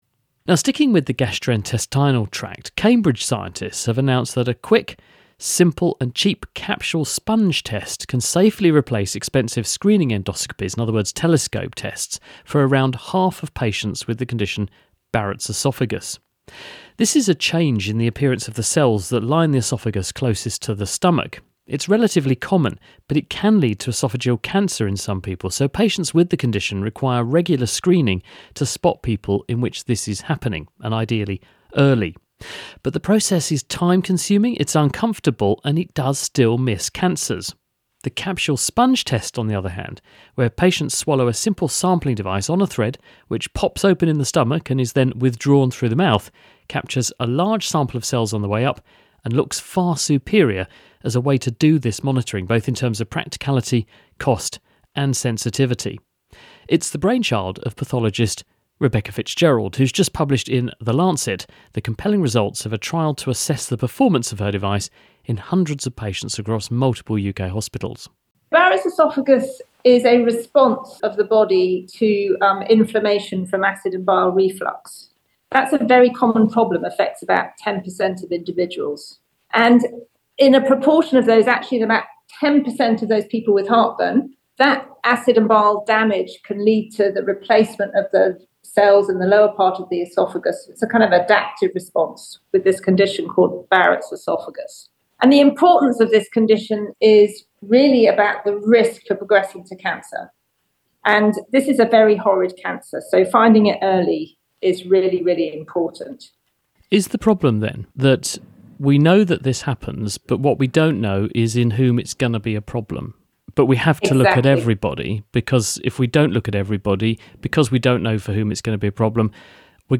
Interviews with Scientists
Interviews about medicine, science, technology and engineering with scientists and researchers internationally...